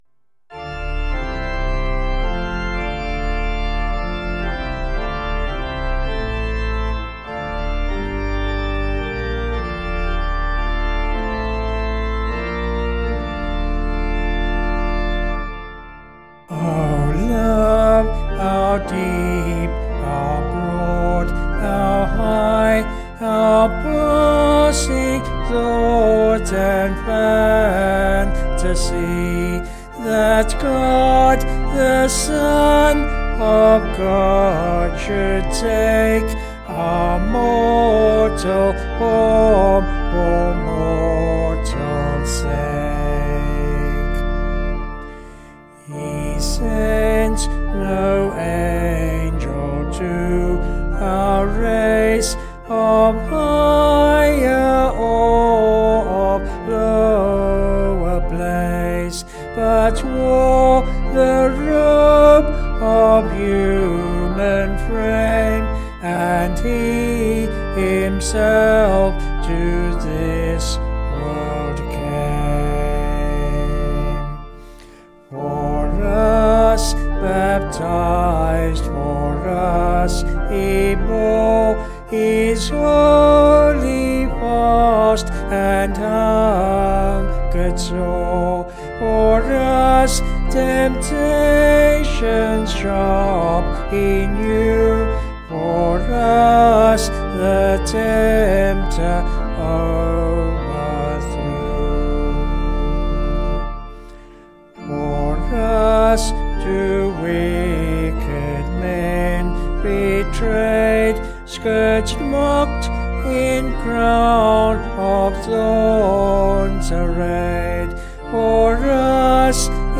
6/Dm
Vocals and Organ   264.2kb Sung Lyrics